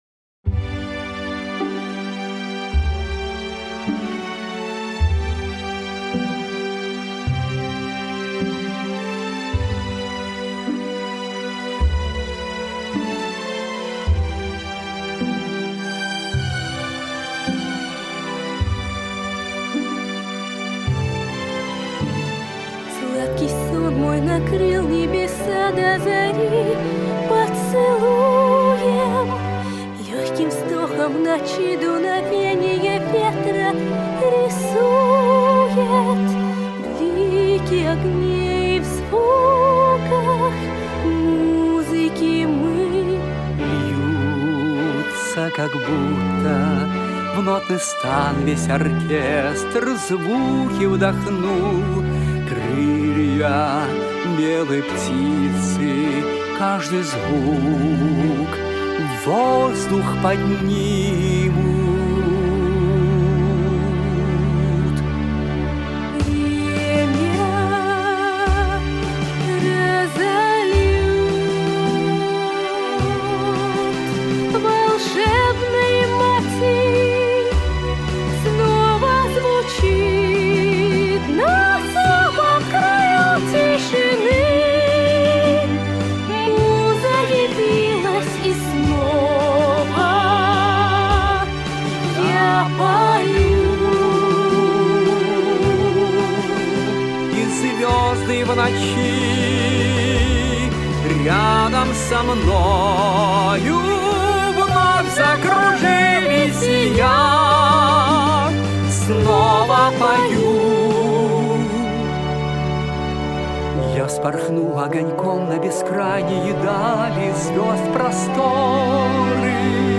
Очень нравится этот дуэт.